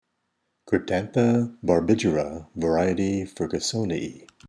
Pronunciation/Pronunciación:
Cryp-tán-tha  bar-bí-ge-ra  var.  fer-gu-sò-ni-ae